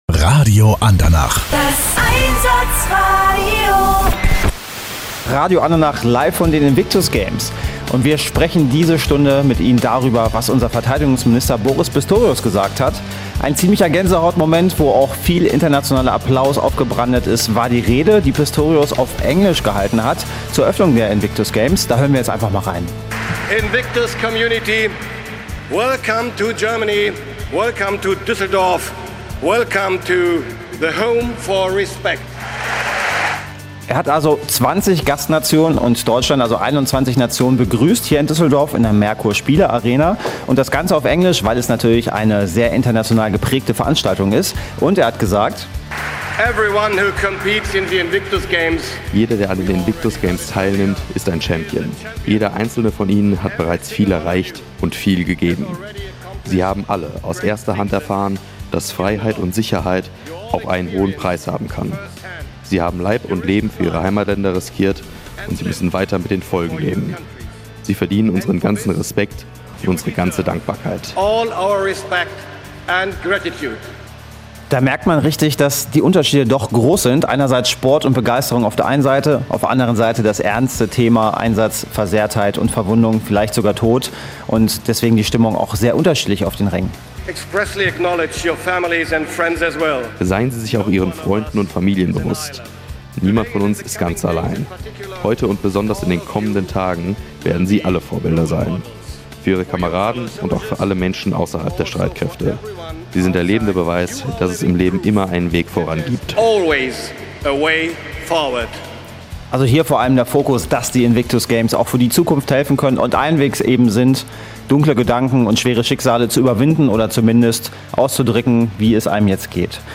Interview Verteidigungsminister
Minister Boris Pistorius am Mikrofon
Radio Andernach hat während der Invictus Games 2023 mit Verteidigungsminister Postorius gesprochen